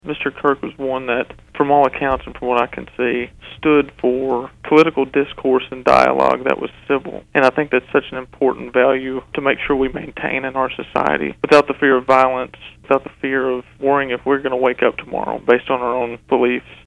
Weakley County Mayor Dale Hutcherson speaks to the impact of Mr. Kirk.